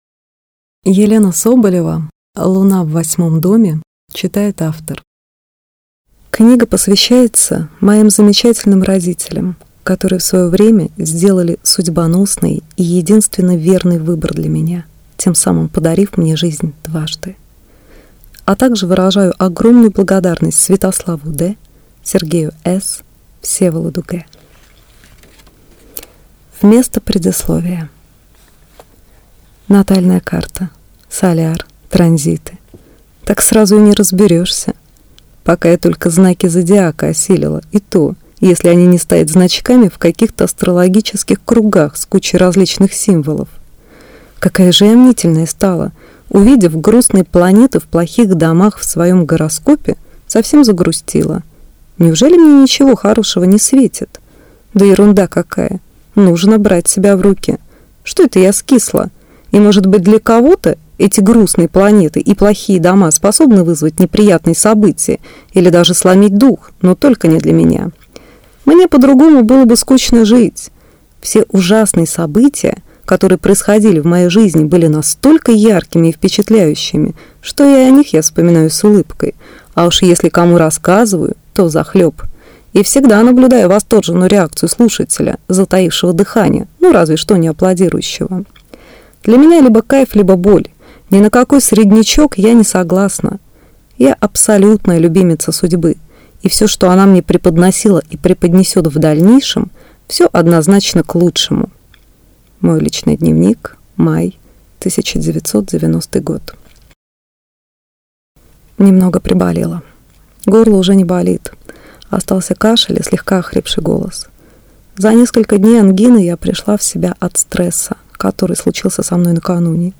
Аудиокнига Луна в восьмом доме | Библиотека аудиокниг